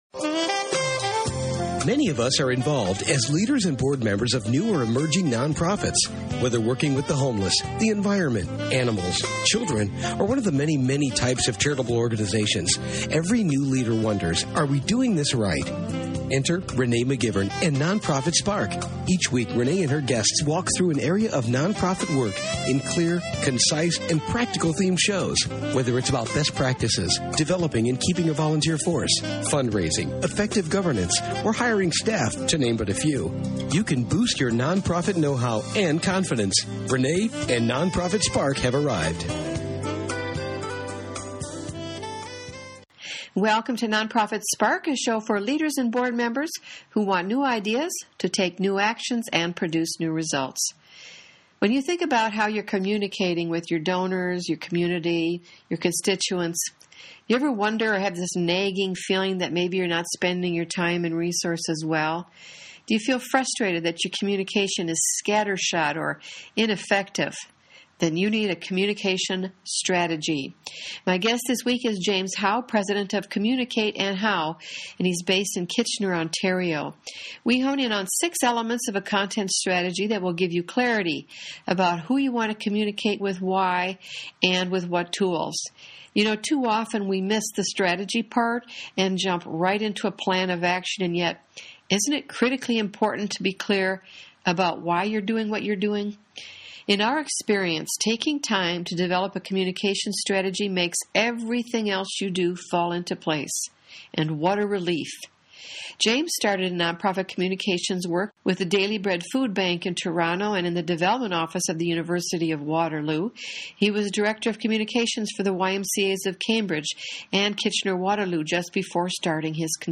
online radio show. We discuss 6 key elements of a nonprofit communications strategy.